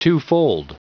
Prononciation du mot twofold en anglais (fichier audio)
Prononciation du mot : twofold